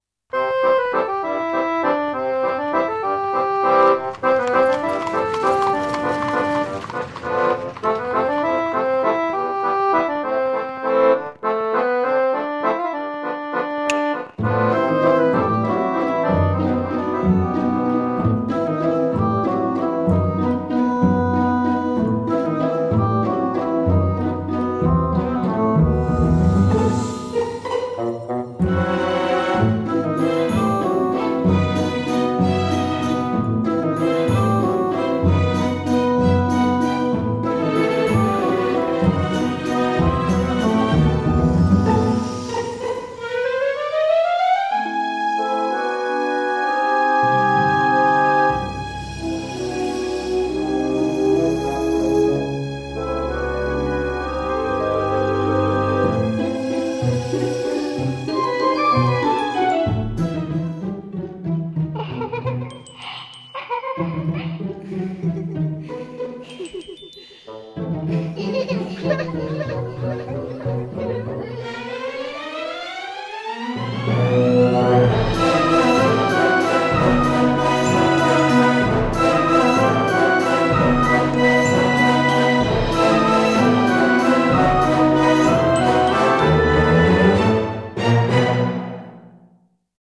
CM風声劇「メリー・サーカス」